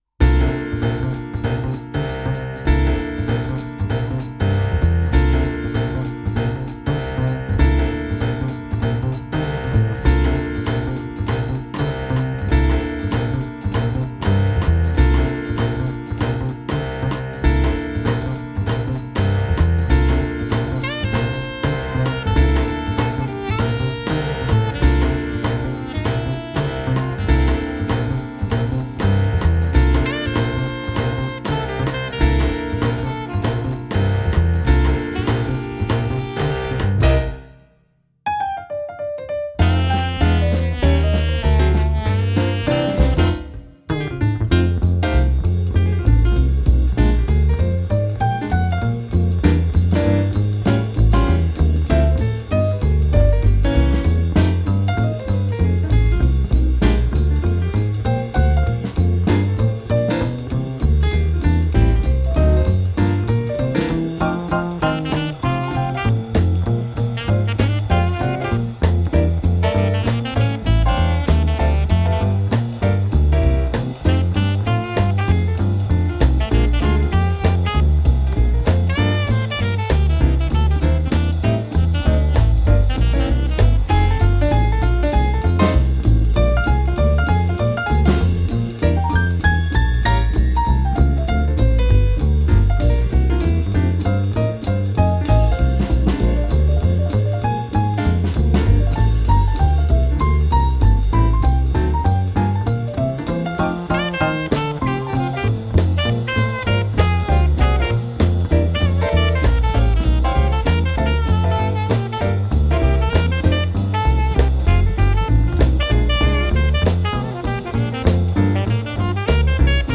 1. Challenge 2026 - Jazz (Ergebnisse)
4/4 auf 195 bpm. 3 Takte Stille -> 2 Takte Einzähler.
Meine Mastering-Kette halte ich dabei subtil mit etwas Kompression, sachtem EQing und einer Tonband-Simulation.